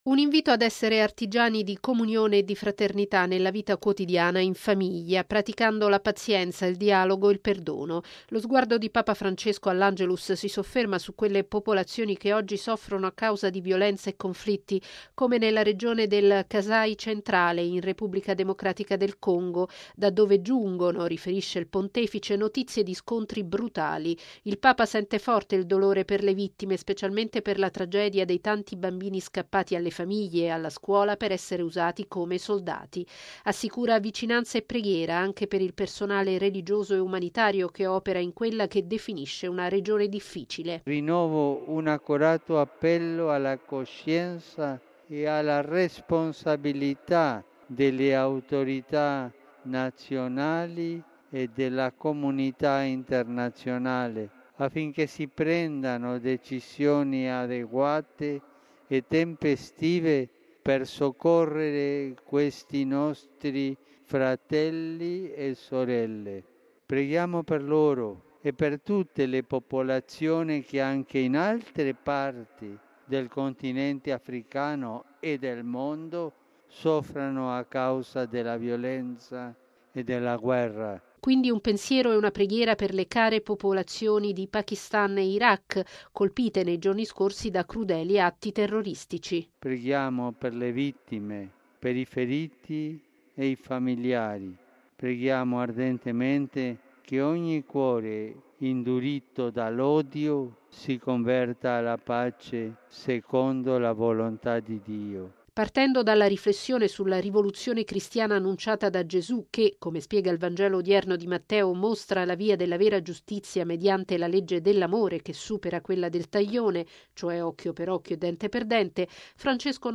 I cuori induriti dall’odio si convertano alla pace, in Africa, in Medio Oriente, in Asia e in tutte le parti del mondo dove si soffre a causa di guerre e violenze. Così il Papa alla preghiera dell’Angelus in Piazza San Pietro, durante la quale ha esortato a rispondere al male col bene, perché - ha detto - la rappresaglia “non porta mai” alla risoluzione dei conflitti, neppure in famiglia.